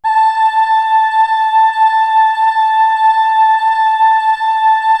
Index of /90_sSampleCDs/Best Service ProSamples vol.55 - Retro Sampler [AKAI] 1CD/Partition C/CHOIR